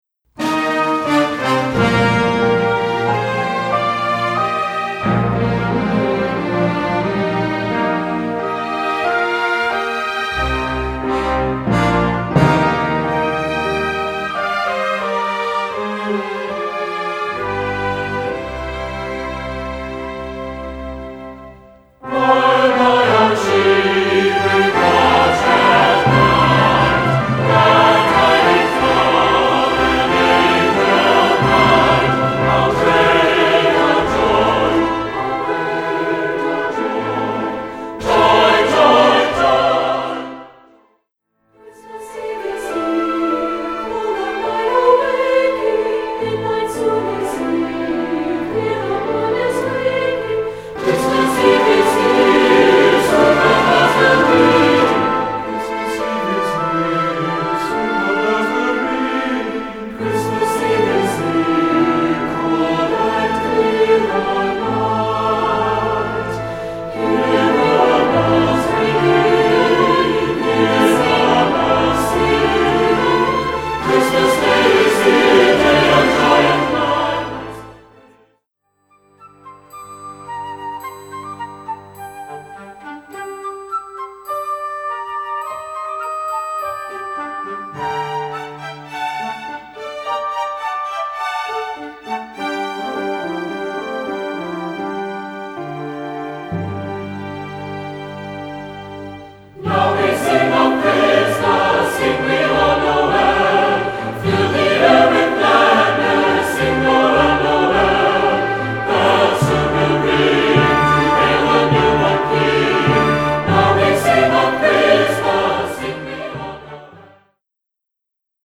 Voicing: Score and Parts